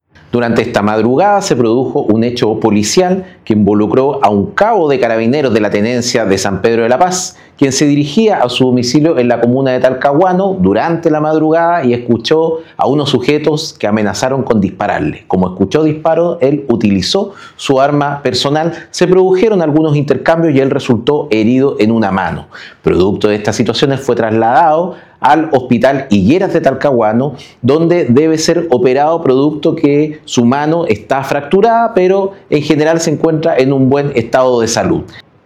El delegado presidencial del Bío Bío, Julio Anativa, añadió que el incidente ocurrió cuando el funcionario se dirigía a su vivienda.
delegado-carabinero-baleado.mp3